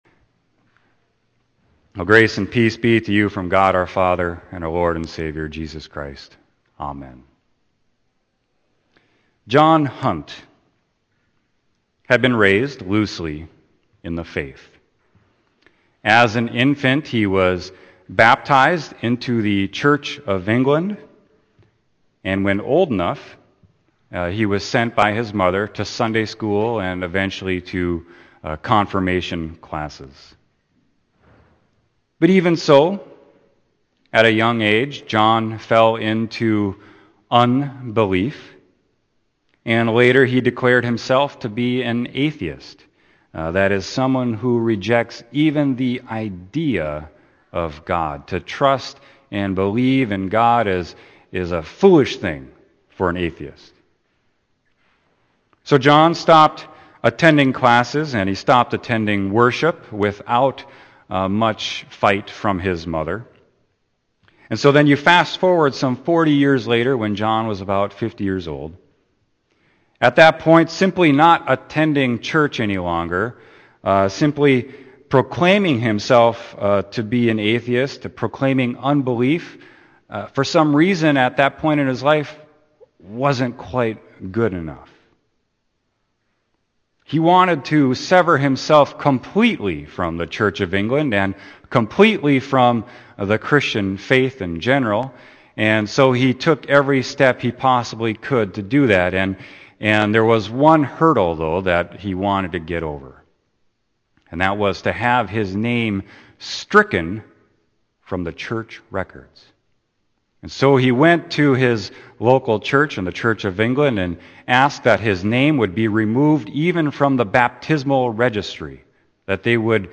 Sermon: Romans 11.29-32